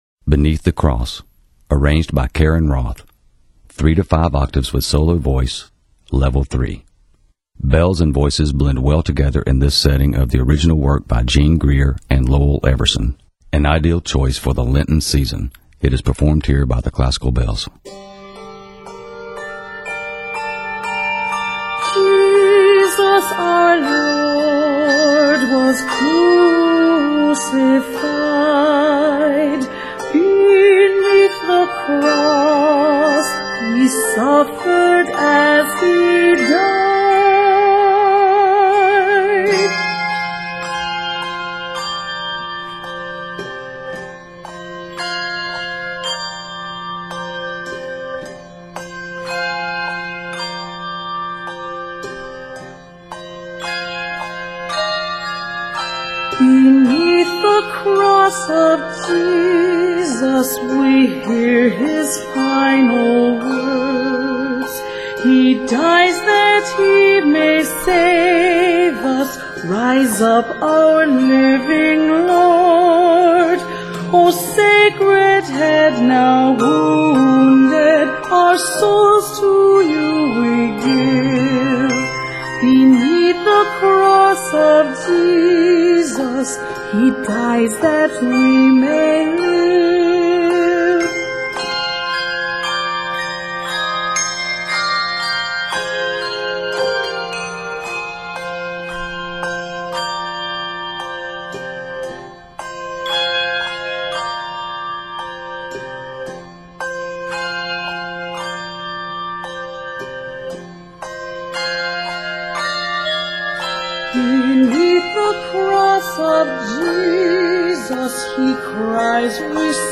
arranged for handbells and solo voice